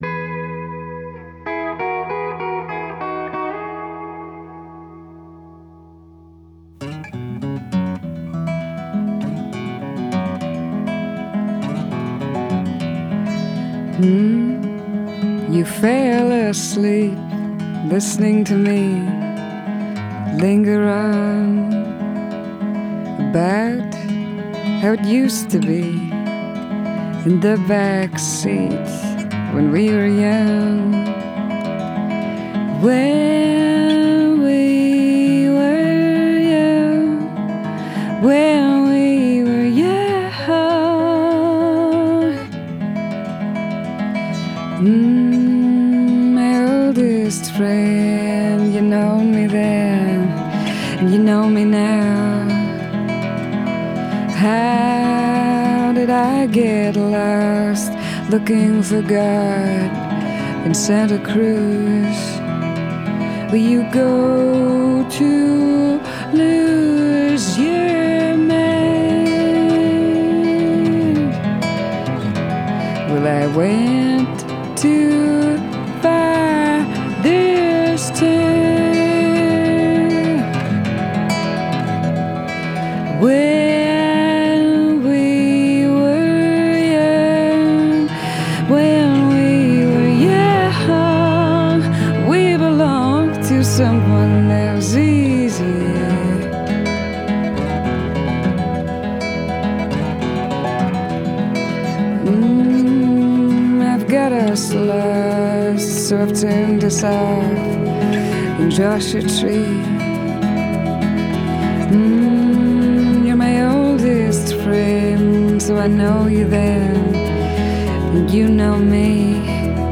Genre: Indie Folk, Alternative